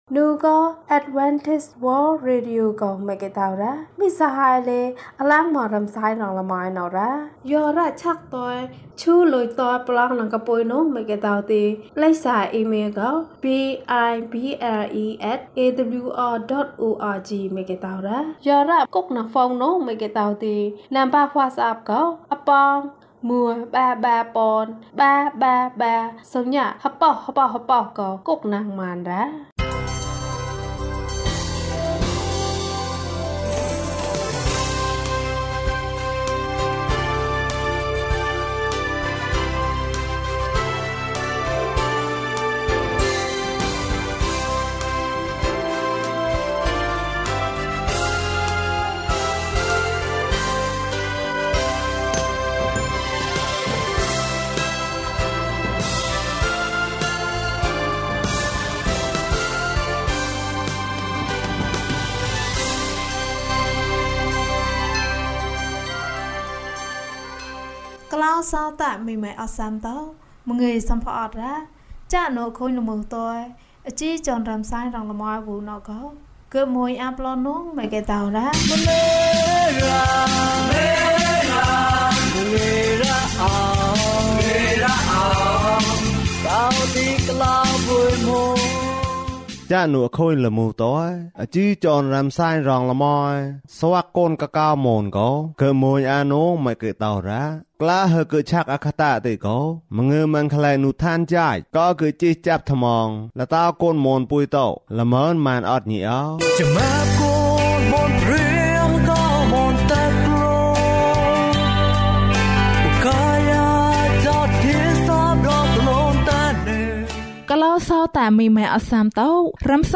ခရစ်တော်ထံသို့ ခြေလှမ်း။၅၃ ကျန်းမာခြင်းအကြောင်းအရာ။ ဓမ္မသီချင်း။ တရားဒေသနာ။